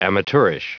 Prononciation du mot amateurish en anglais (fichier audio)
Prononciation du mot : amateurish